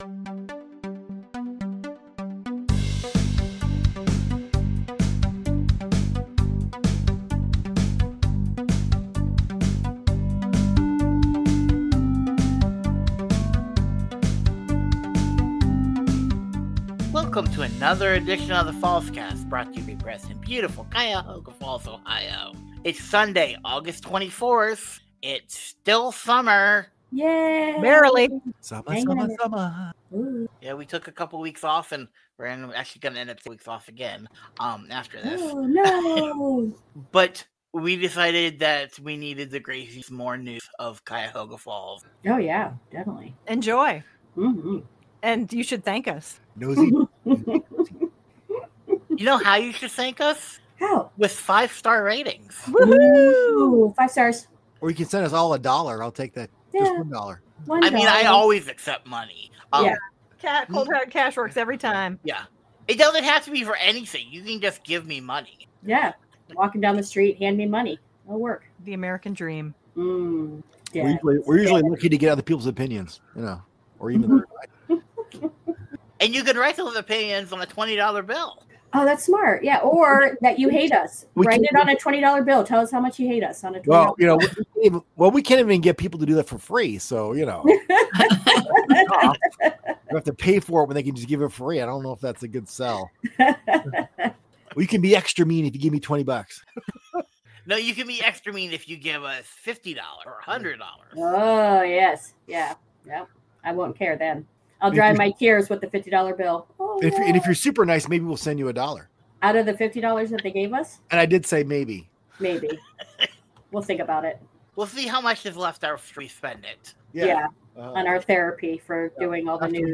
*A discussion on trans rights *Cell phone fires *Cuyahoga Falls Collection *Mud Brook Trail *And.